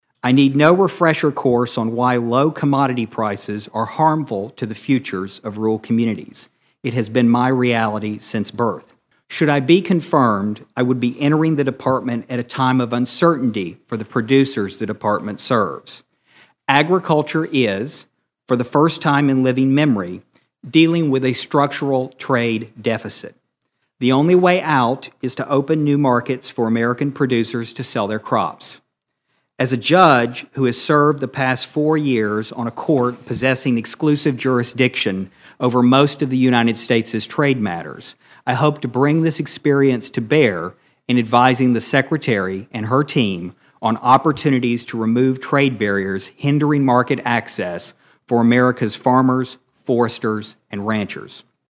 Obion County’s Stephen Vaden answered questions from lawmakers during his confirmation hearing in Washington on Tuesday.